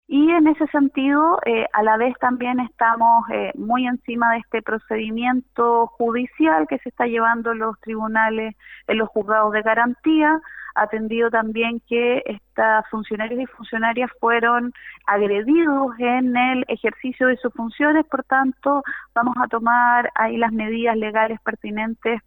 Lo anterior, molestó a las dos personas que también llegaron a vacunarse, las que tras una discusión agredieron al personal de salud, hecho que fue repudiado por la alcaldesa de San Antonio, Constanza Lizana, quien señaló que el municipio va a tomar medidas legales.
cu-alcaldesa-san-antonio-.mp3